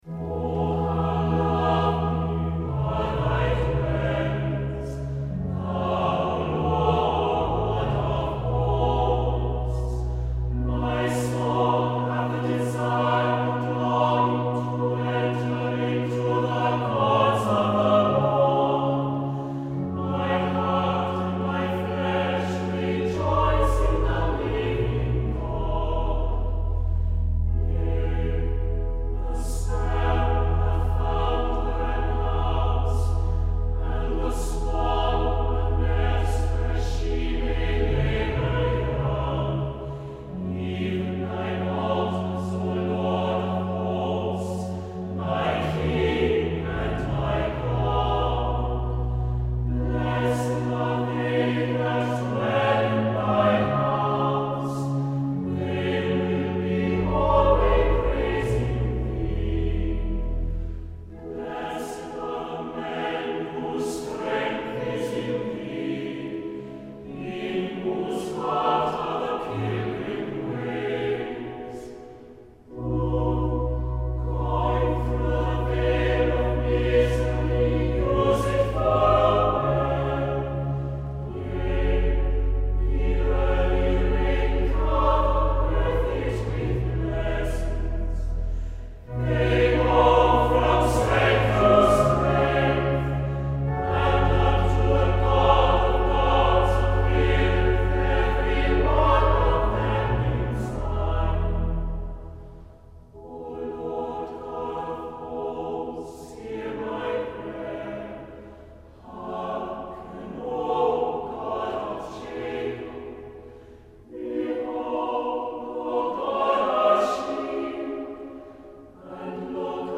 Opening van deze zondag met muziek, rechtstreeks vanuit onze studio.
Anglicaanse Chant , Psalm 84, O How Amiable; 2.
orgelimprovisatie